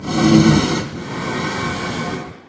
Minecraft Version Minecraft Version latest Latest Release | Latest Snapshot latest / assets / minecraft / sounds / mob / blaze / breathe1.ogg Compare With Compare With Latest Release | Latest Snapshot
breathe1.ogg